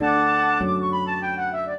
flute-harp
minuet0-7.wav